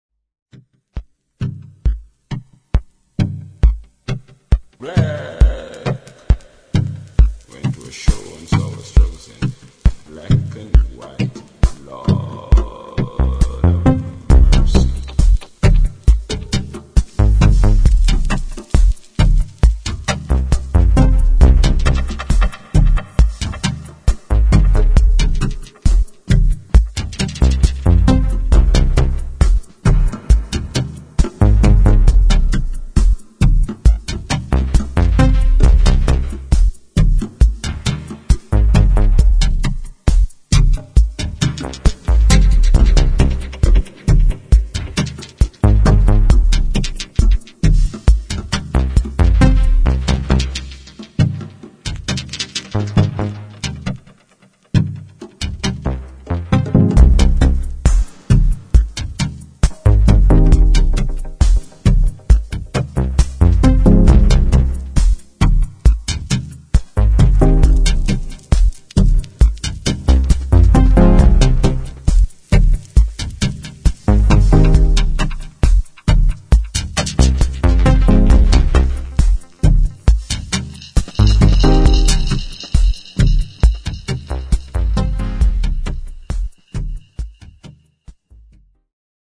[ DUB / DUB TECHNO ]